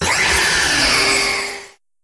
Cri de Lunala dans Pokémon Soleil et Lune.